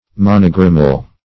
Monogrammal \Mon"o*gram`mal\, a.